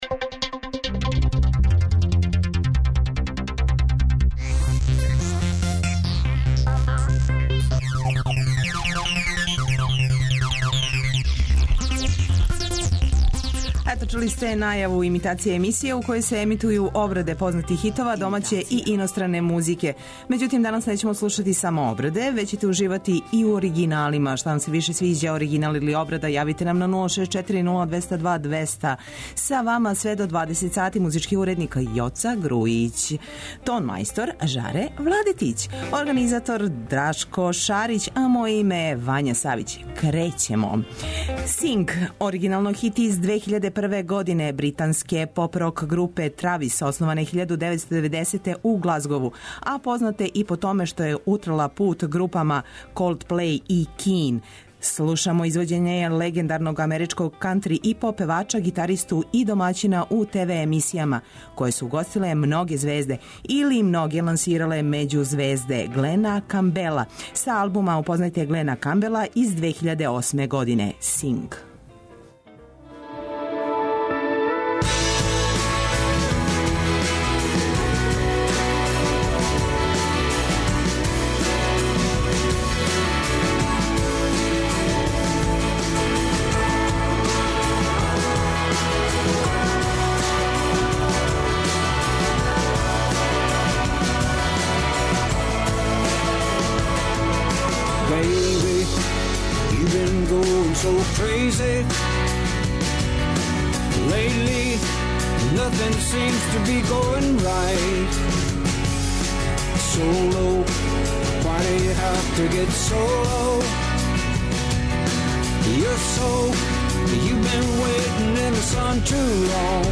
У данашњој емисији емитоваћемо обраде песама